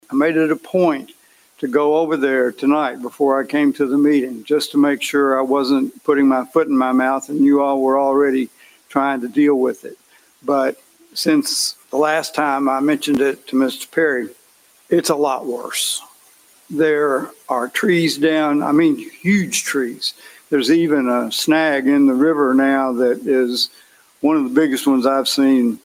Mayor JR Knight held a community budget discussion prior to this year’s budget allocation process.